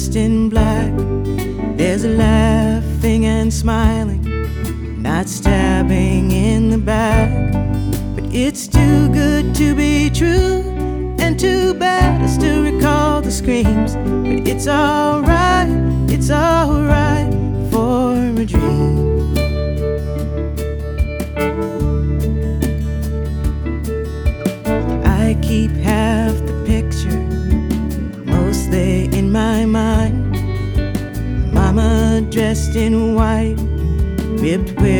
Alternative Folk Contemporary Folk Rock Adult Alternative
Жанр: Рок / Альтернатива / Фолк